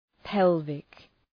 Shkrimi fonetik {‘pelvık}